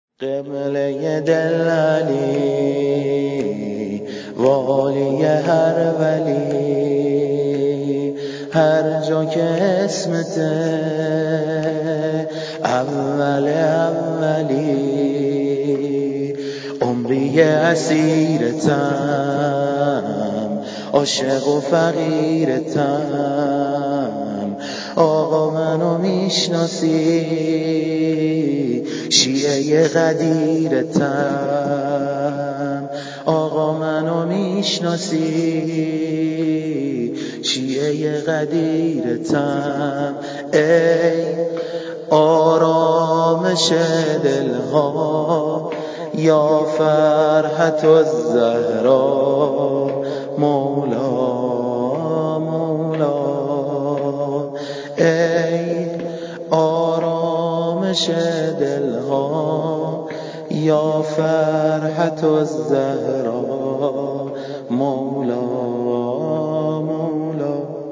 عید غدیر
شور ، سرود